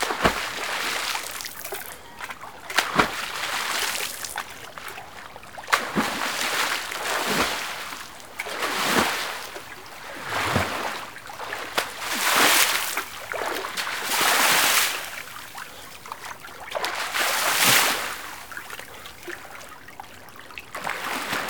SPLASH AM05R.wav